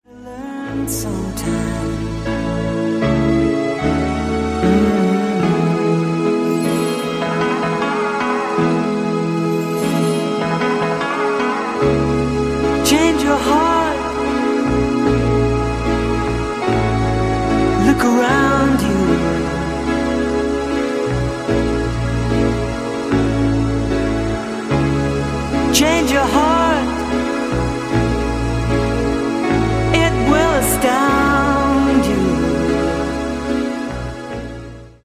Genere:   Pop | Rock | Dance